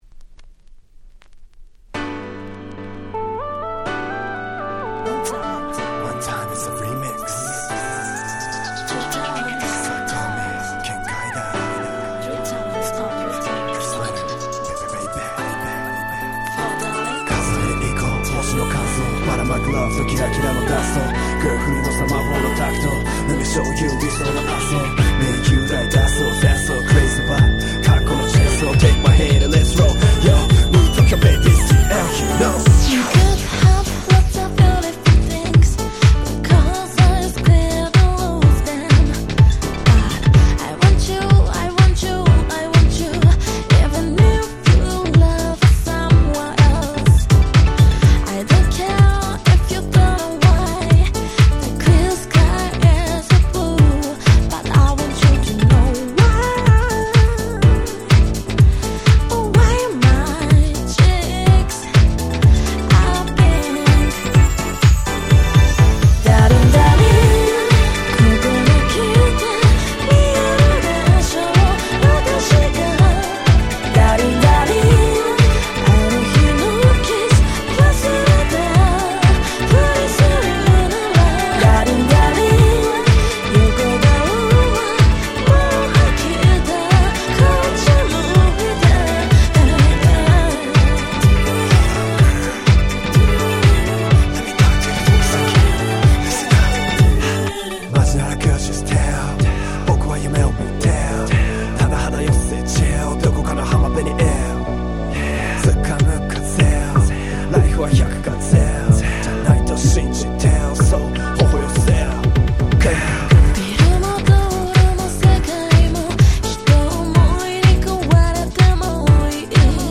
09' Nice Cover R&B !!
超キャッチー！！
原曲とはまた違ったアレンジでめちゃ新鮮！！